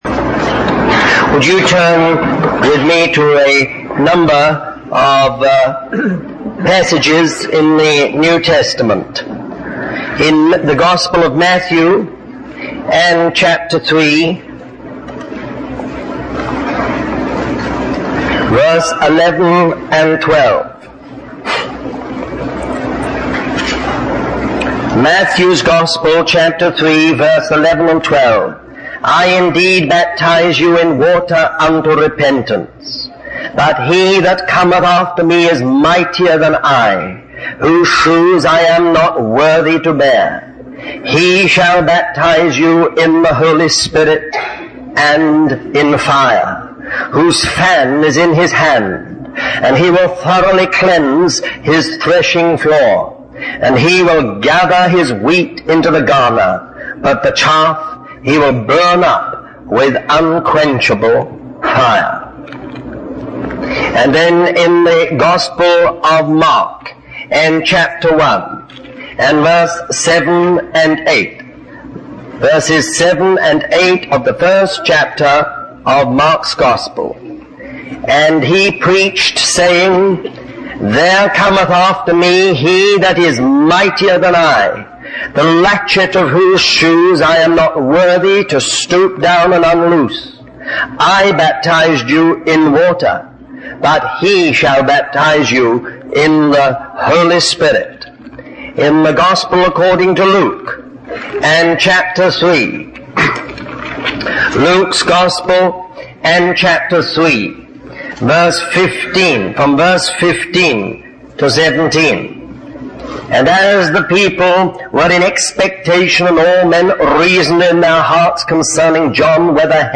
Christian Family Conference July 5, 1984